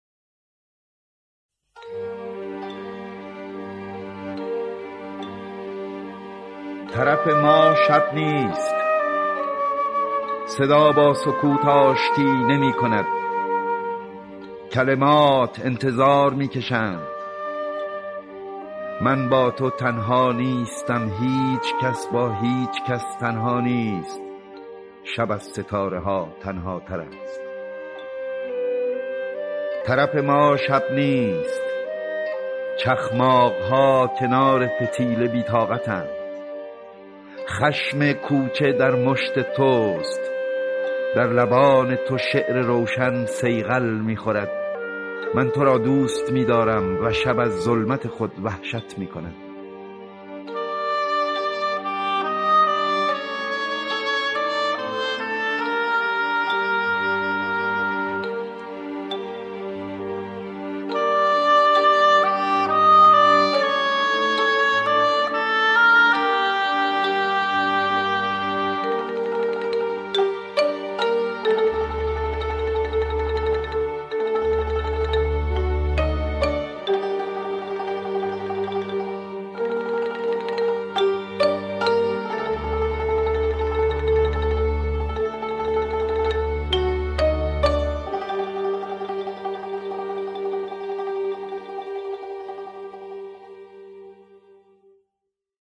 دکلمه شعر تو را دوست می دارم با صدای احمد شاملو